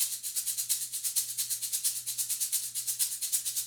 Shaker 13.wav